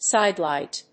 アクセント・音節síde・lìght